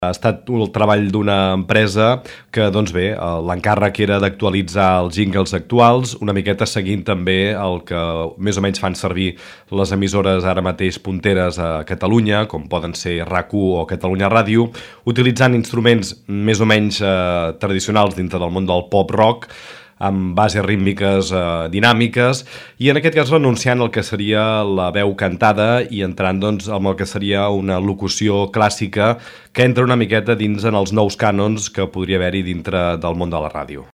molt més sonors, més dinàmics i vius
nous jingles